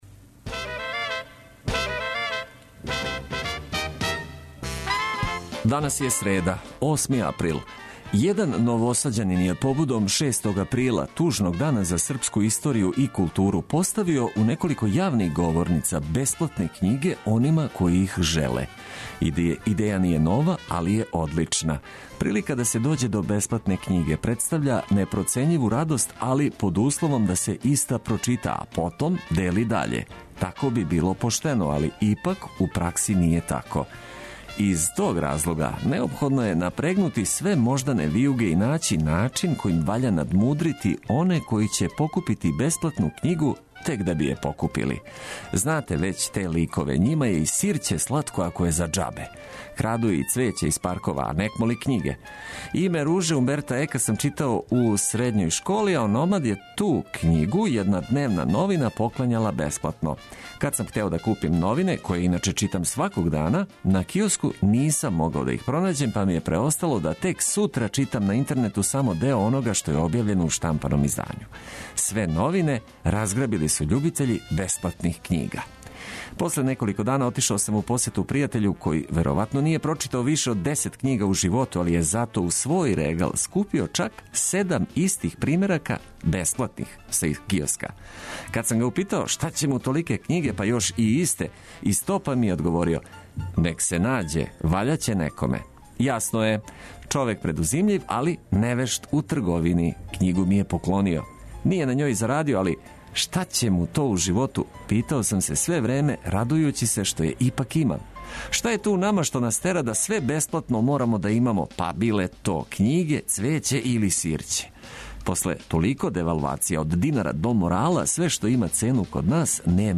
Нека дан почне уз обиље добре музике коју ћемо прошарати корисним информацијама уз пријатне гласове ваших радио пријатеља.